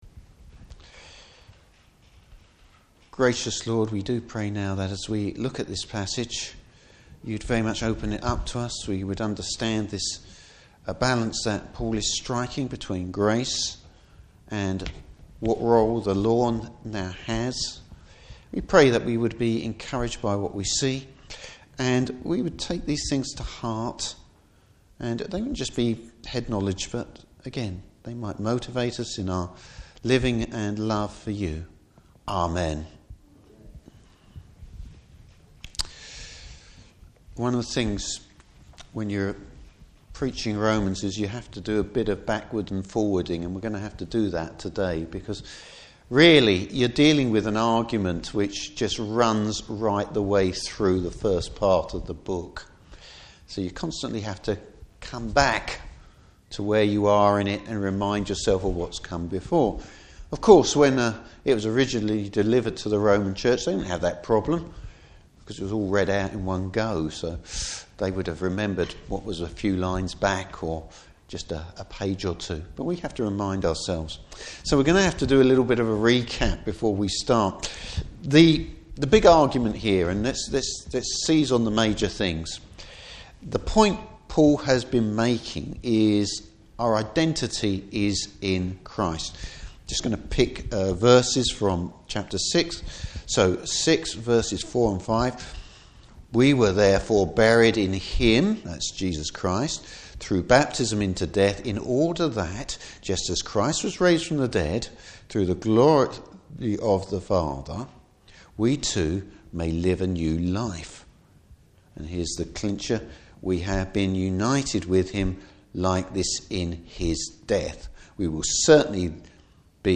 Service Type: Morning Service What’s the Christians relationship with the Law?